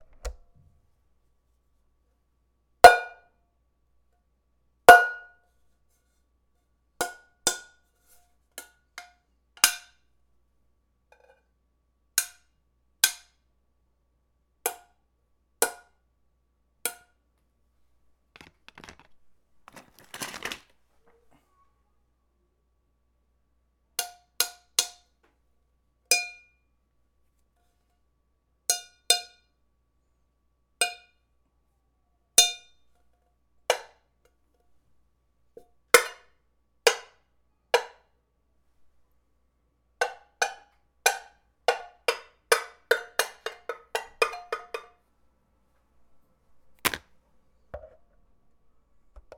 Bongs 2
bell bing bong chime ding sound effect free sound royalty free Sound Effects